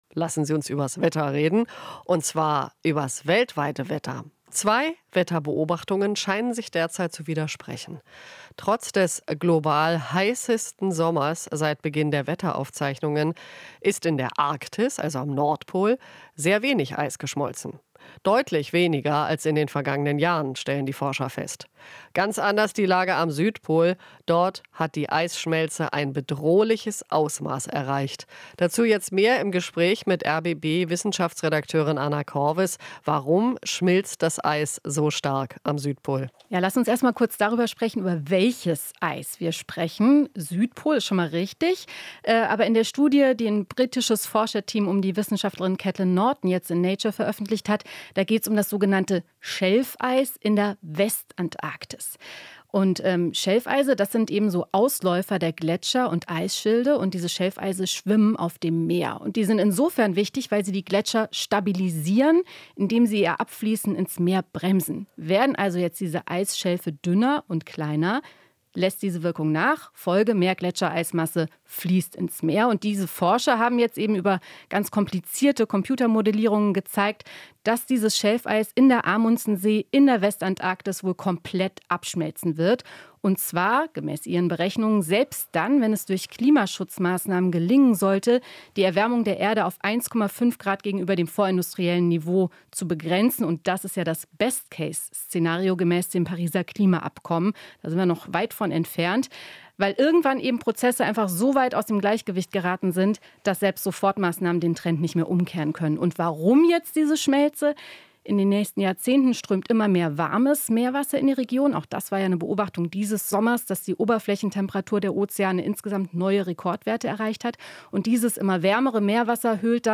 Interview - Schelfeis in der Antarktis schmilzt bedrohlich schnell